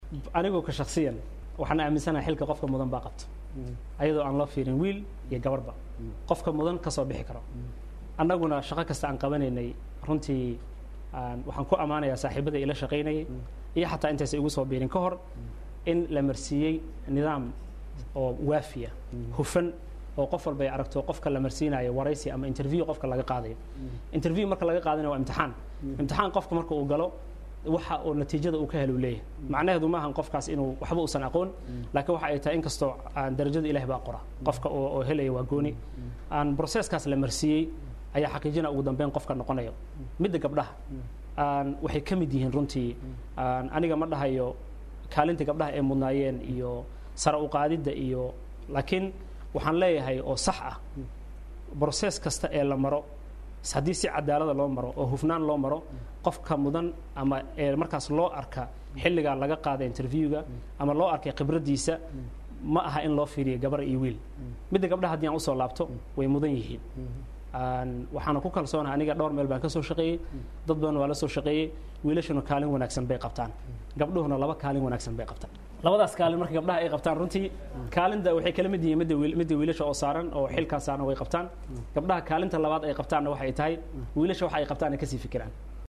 Gudoomiyaha gobolka Banaadir oo ka waramay laba kaalin ee haweenku qabtaan: MAQAL
Gudoomiyaha gobolka Banaadir ayaa ka waramay laba kaalin ee haweenku qabtaan xilli wax laga weydiiyay tirada haweenka ee uu u magacabay gobolka oo aad loogu amaanay.